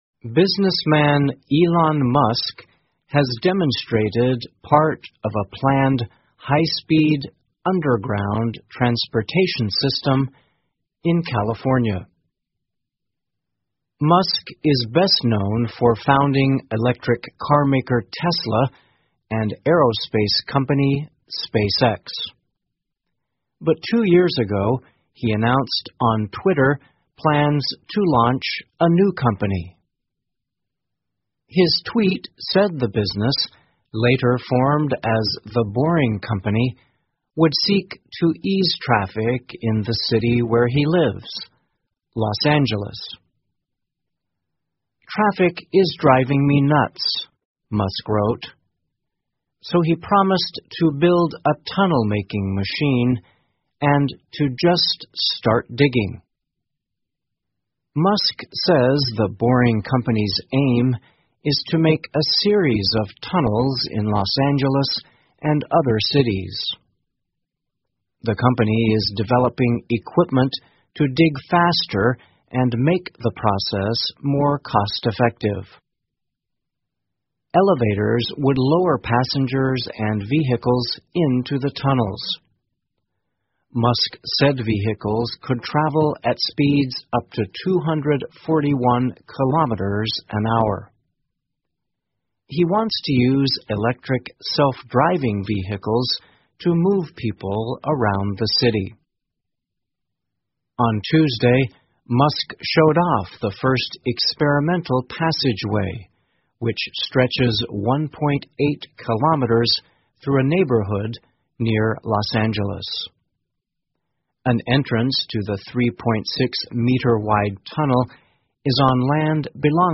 VOA慢速英语--埃隆·马斯克展示高速地下隧道 听力文件下载—在线英语听力室